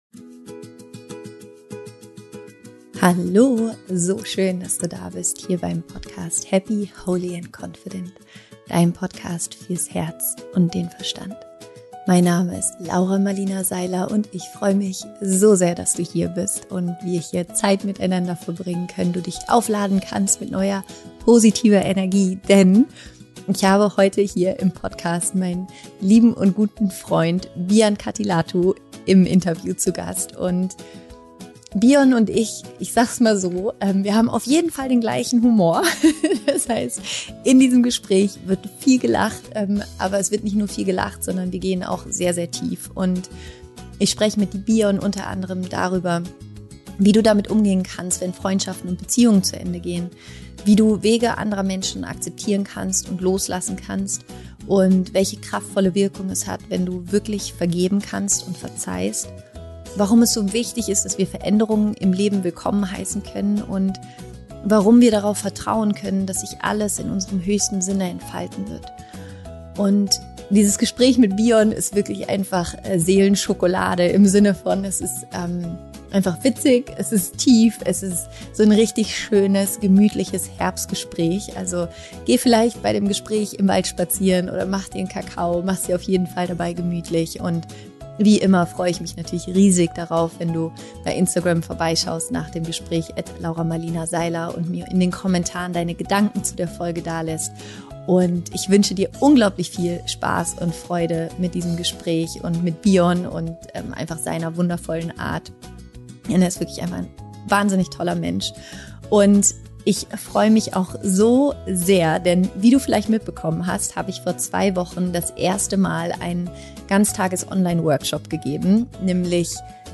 Wie du lernst, Menschen loszulassen und zu verzeihen - Interview mit Biyon Kattilathu
Im Interview spreche ich mit dem unglaublich inspirierenden und humorvollen Biyon Kattilathu darüber, wie du damit umgehen kannst, wenn sich dein Umfeld verändert und warum es manchmal notwendig ist loszulassen.